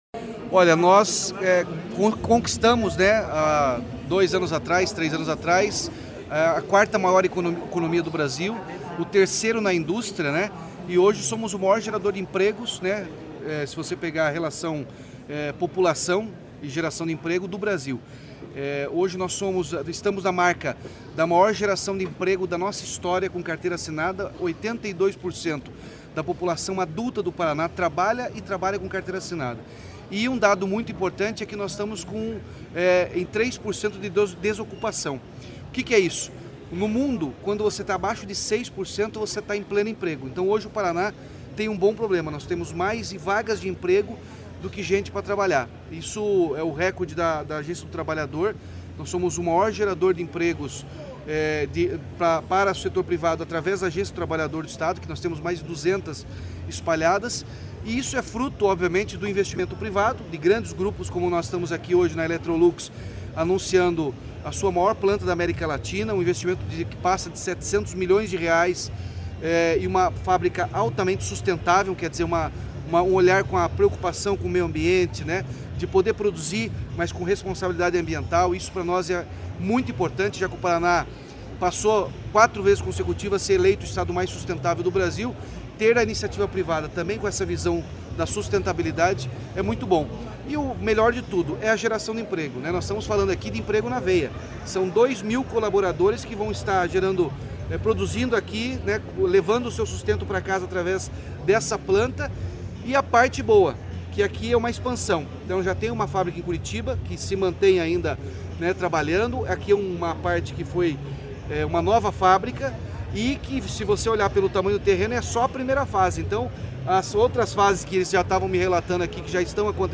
Sonora do governador Ratinho Junior sobre a nova fábrica da Electrolux em São José dos Pinhais